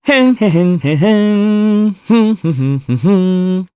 Kasel-Vox_Hum_jp.wav